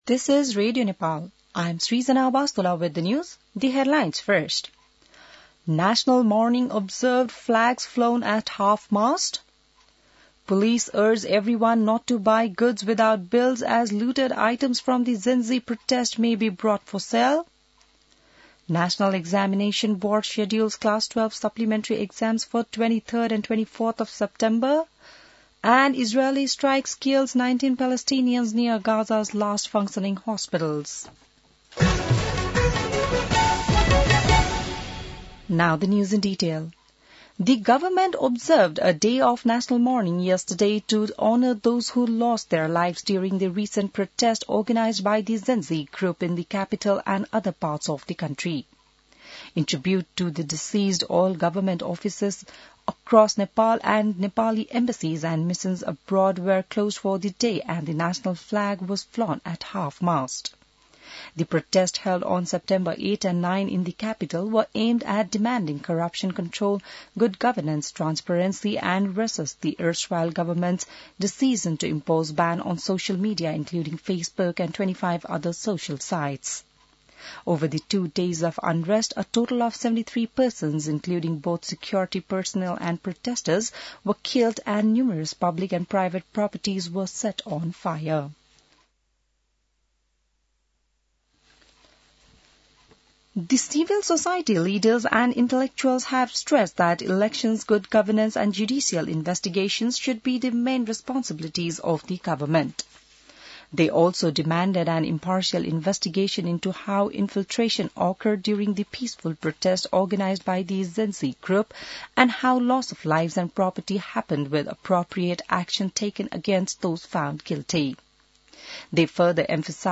बिहान ८ बजेको अङ्ग्रेजी समाचार : २ असोज , २०८२